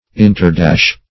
Search Result for " interdash" : The Collaborative International Dictionary of English v.0.48: Interdash \In`ter*dash"\, v. t. [imp.